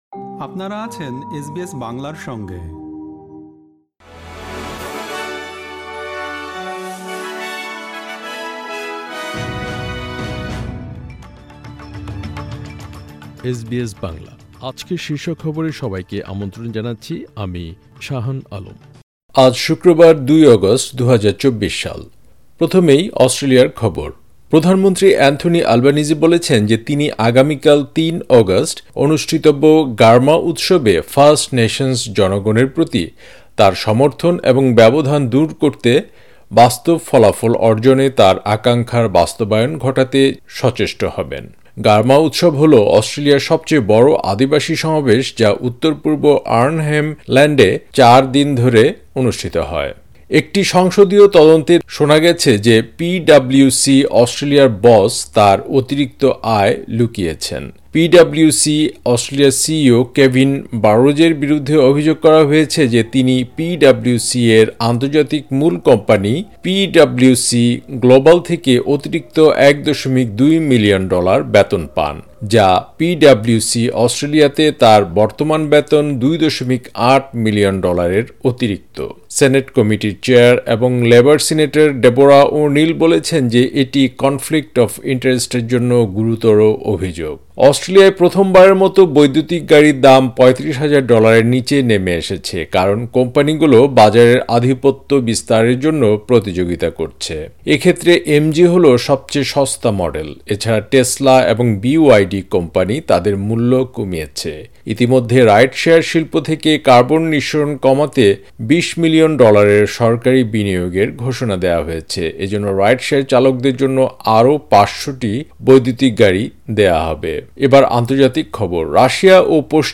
এসবিএস বাংলা শীর্ষ খবর: ২ অগাস্ট, ২০২৪